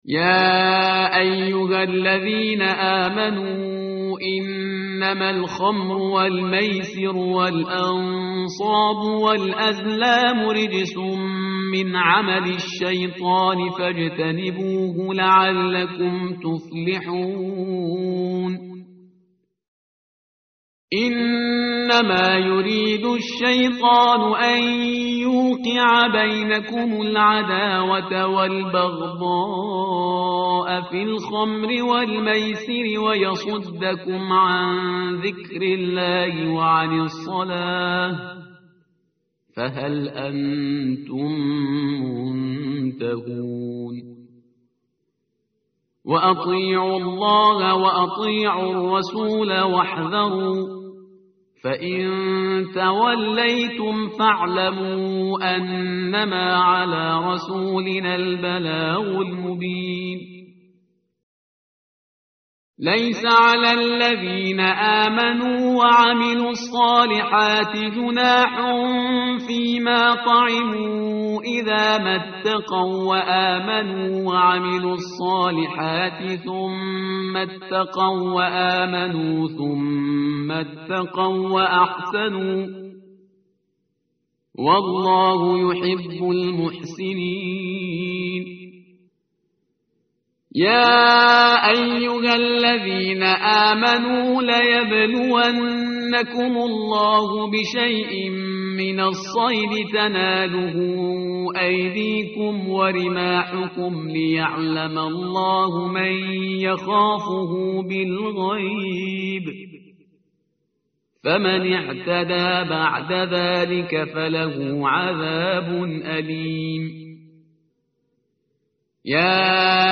متن قرآن همراه باتلاوت قرآن و ترجمه
tartil_parhizgar_page_123.mp3